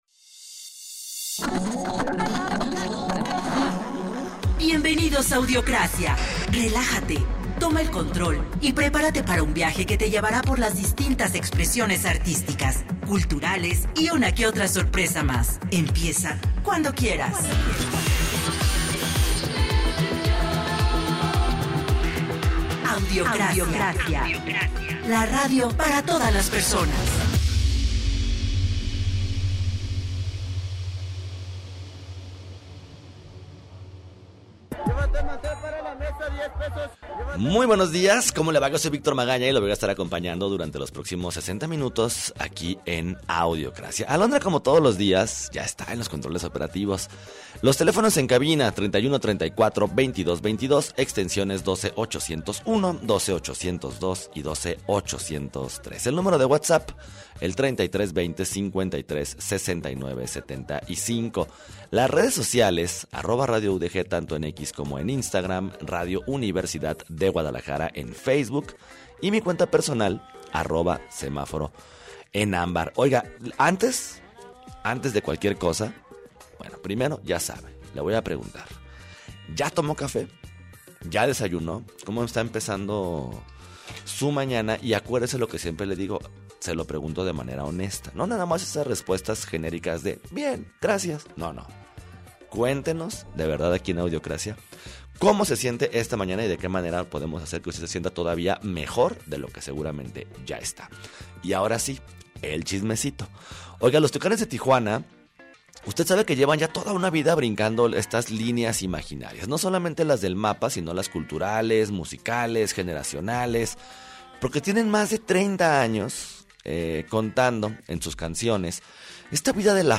tenemos a una invitada muy especial.